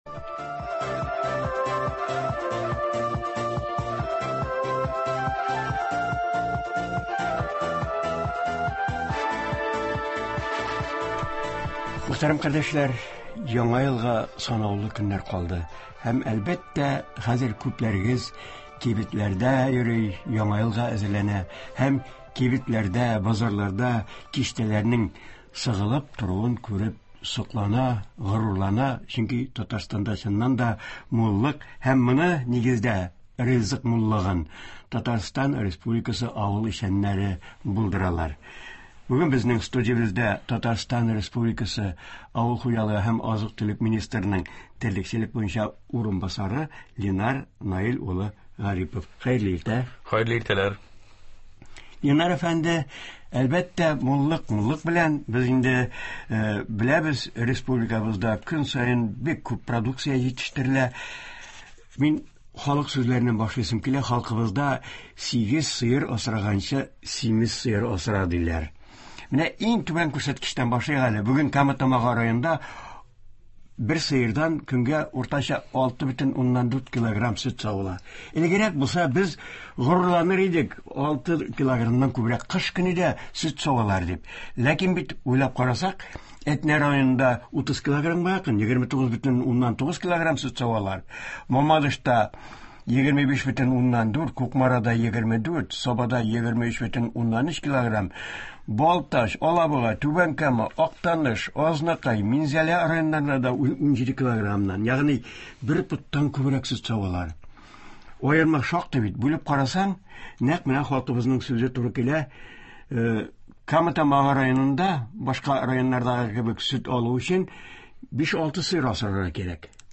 Туры эфир (23.12.20)
Болар хакында авыл хуҗалыгы һәм азык-төлек министры урынбасары Ленар Гарипов сөйли.